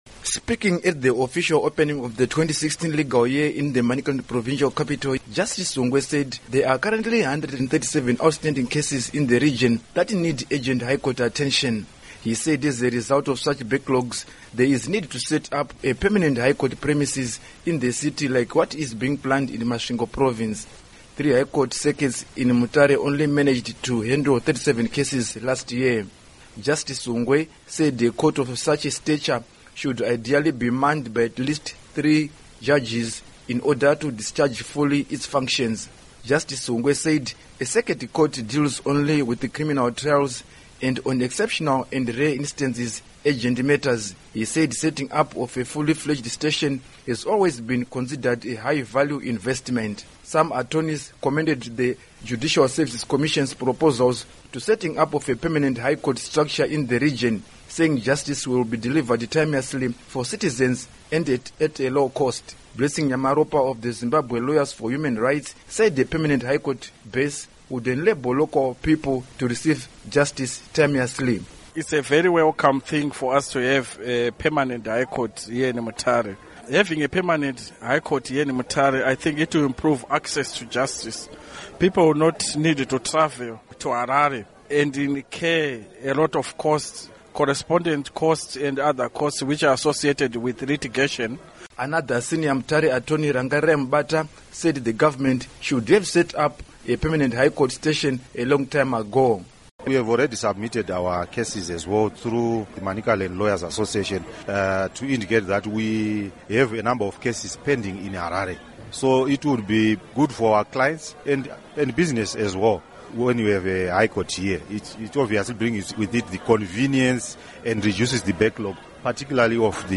Report on Mutare High Court